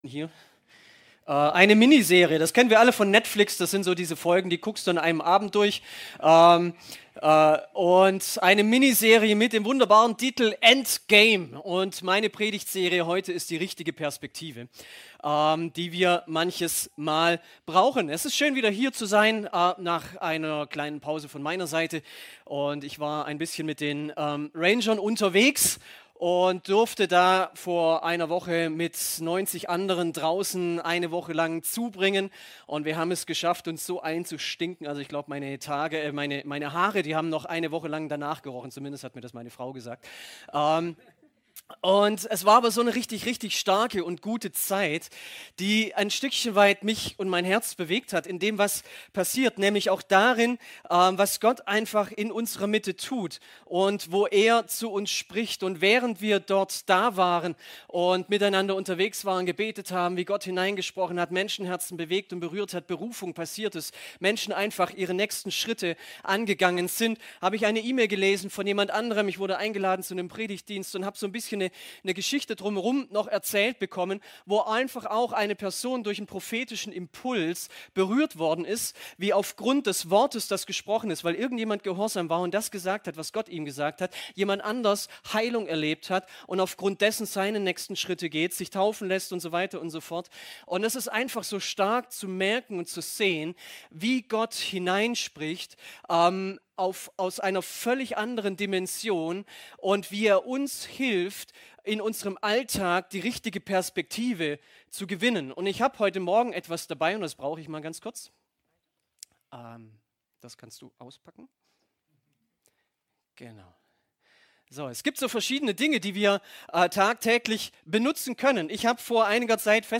Predigten | Panorama Kirche Göppingen :: verändert | gemeinsam | für Andere